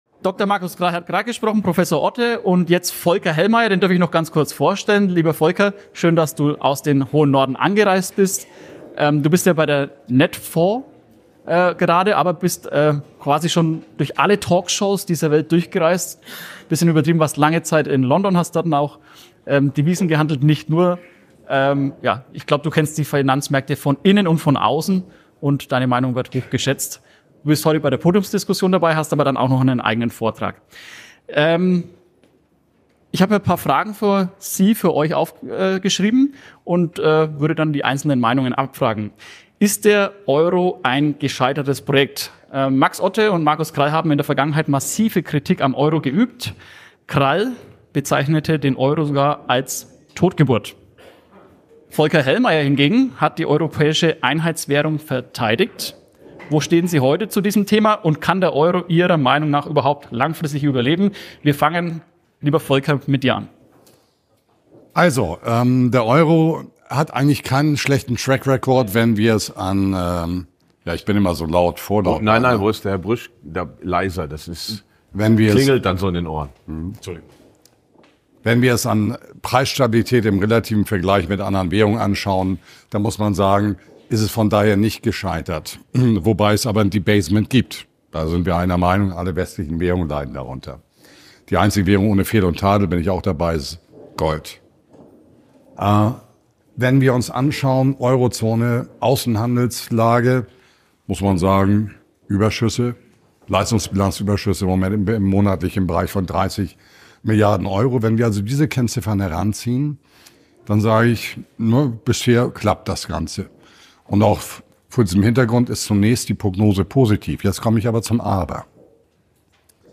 In dieser packenden Diskussion